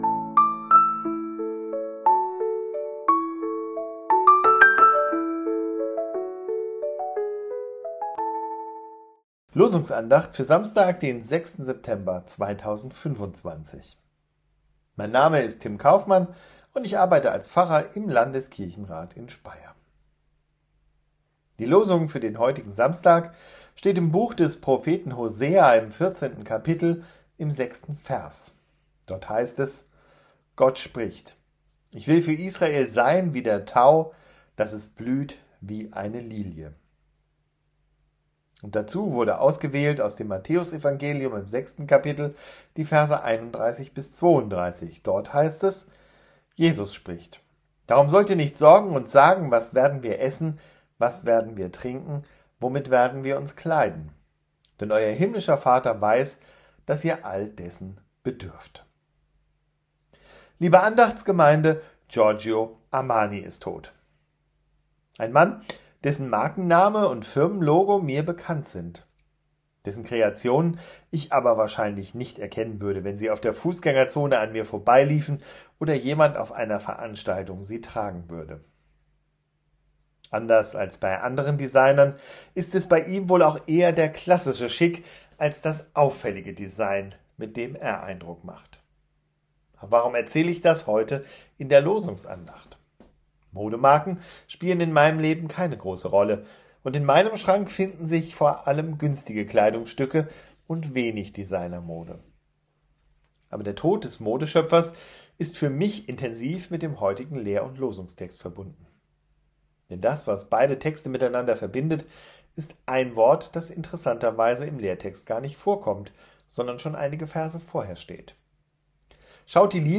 Losungsandacht für Samstag, 06.09.2025 – Prot.